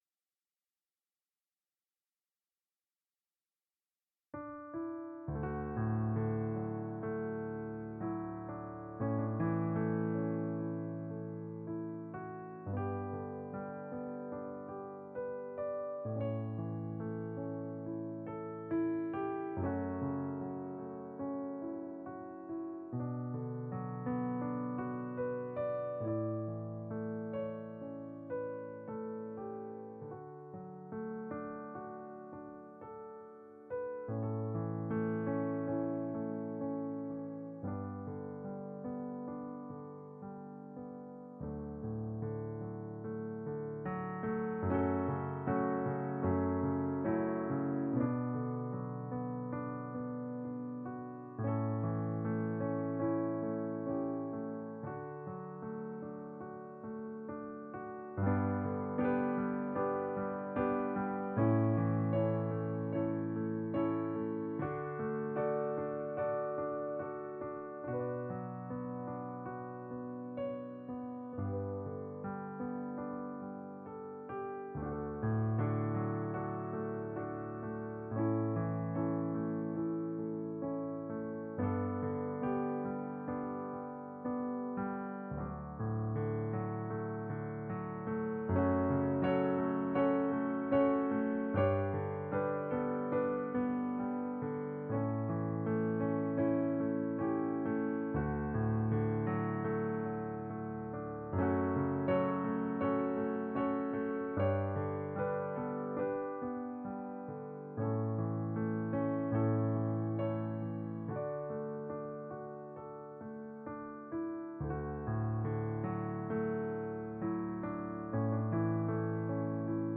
Voicing/Instrumentation: Piano Solo